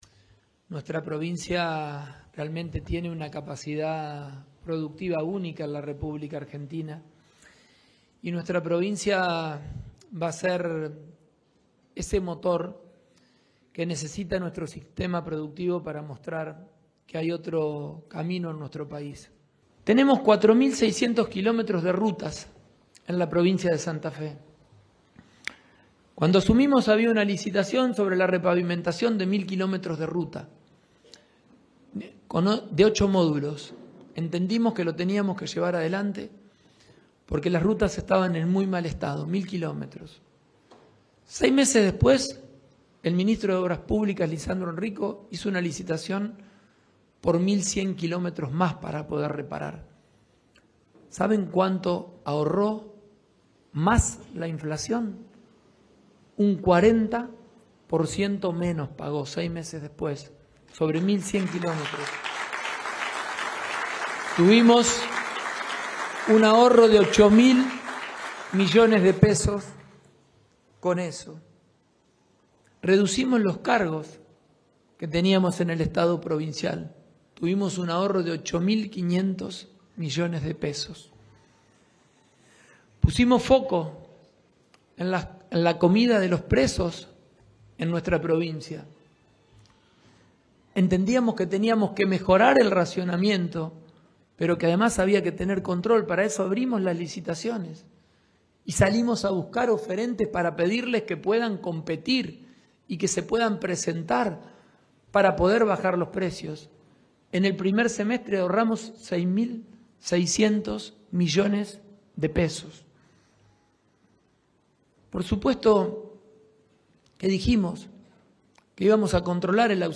El gobernador de la provincia, Maximiliano Pullaro, encabezó este martes en la sede de Gobierno de Rosario, la presentación del Plan de Gasoductos para el Desarrollo.
AUDIO PULLARO